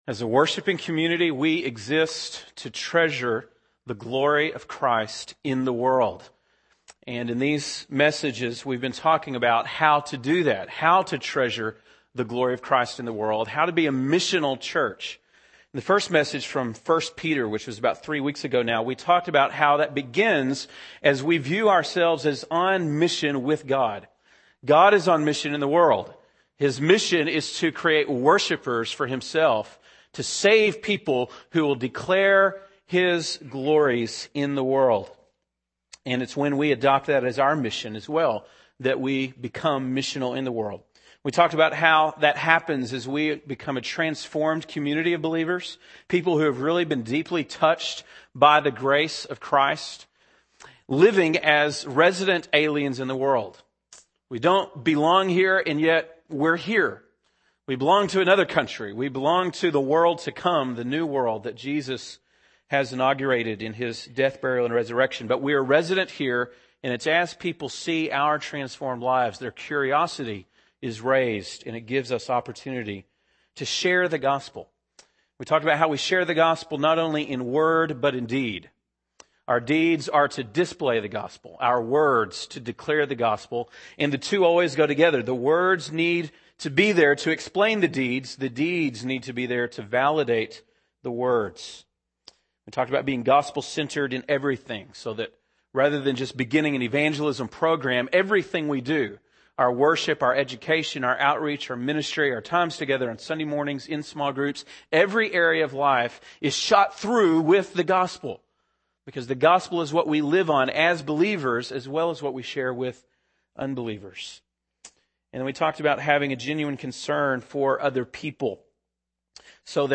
February 17, 2008 (Sunday Morning)